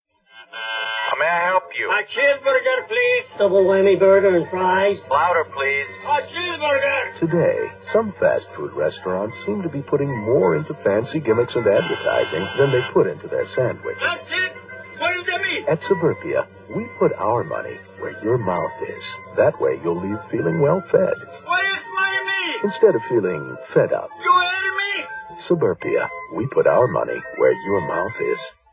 Have a listen to a vintage Suburpia TV ad. It is thought this ad spawned the later "Where's the Beef" series of ad's by a popular national hamburger chain.
Suburpia 1980 TV ad